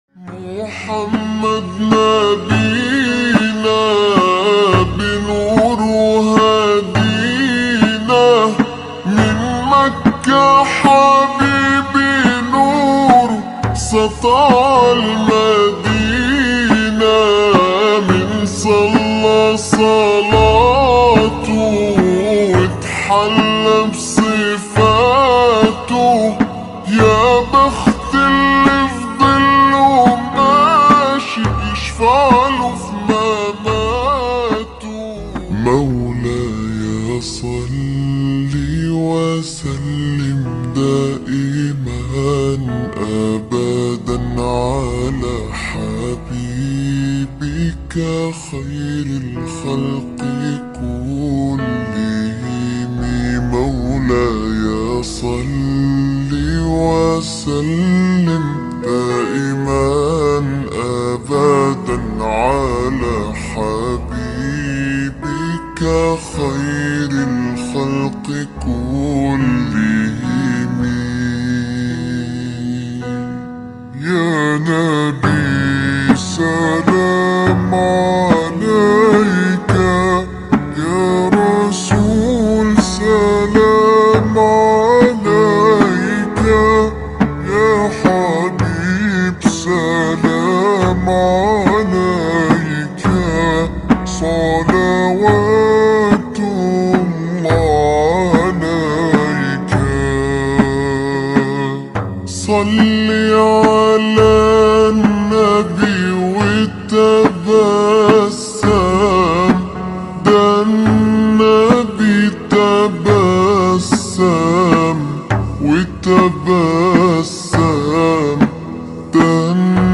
SLOWED+REWERB